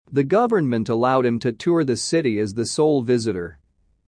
ディクテーション第1問
【ややスロー・スピード】